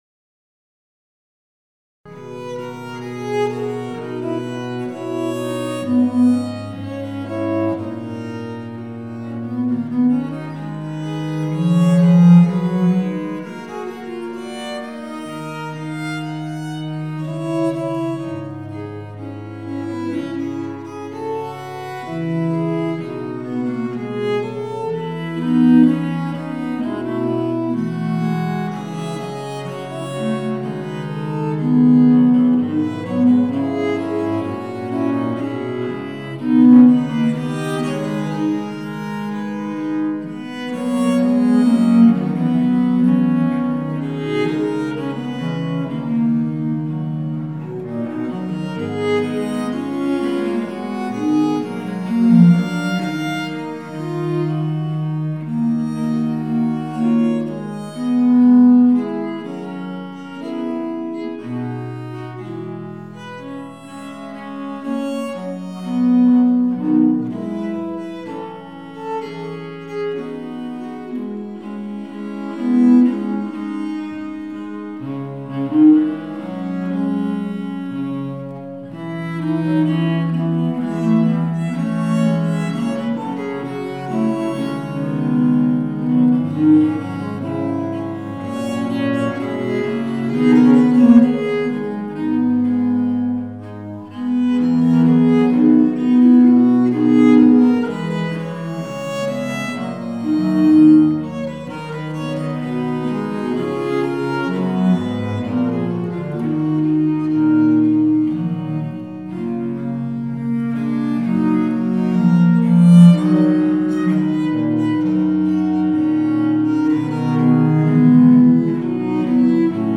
Viols